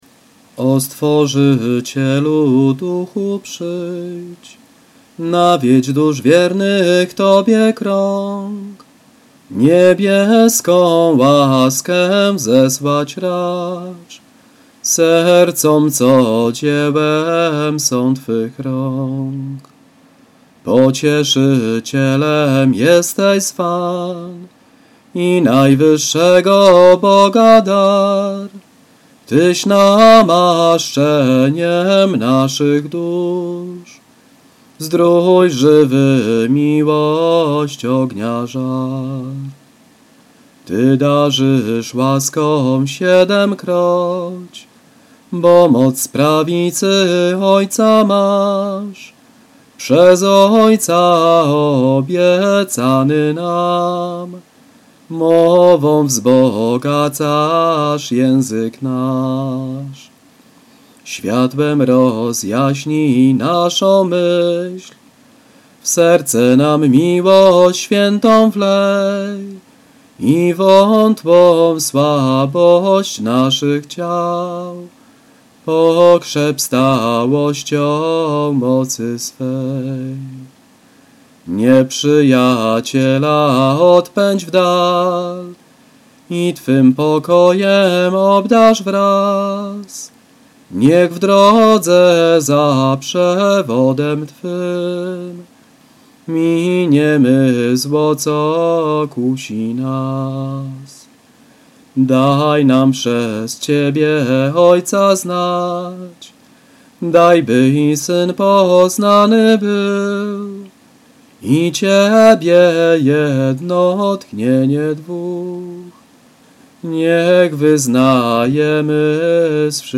Spróbuję zaśpiewać,
ale jestem amatorem.
hymn.mp3